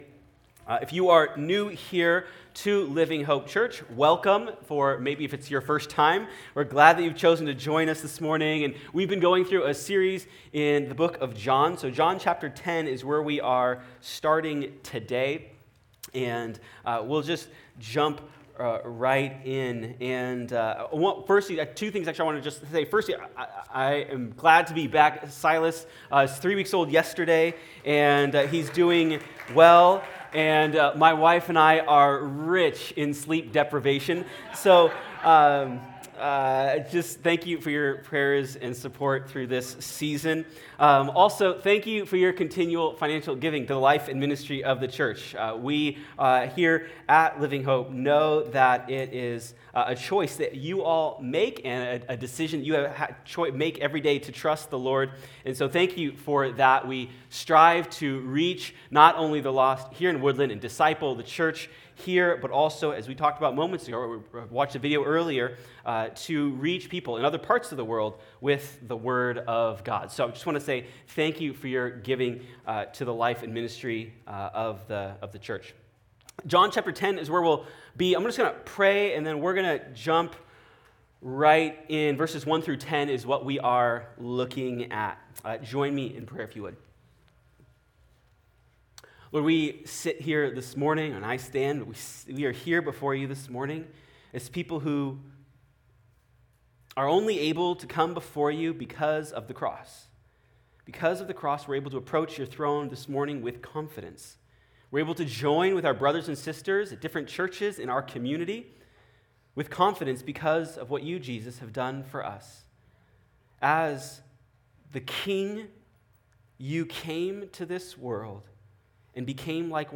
In this passage, Jesus shares a memorable illustration about how to find salvation and shows us the kind of life we can have when we trust Him. Sermon